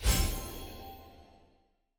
sfx-loot-upgrade-bar-incomplete.ogg